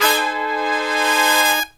LONG HIT03-L.wav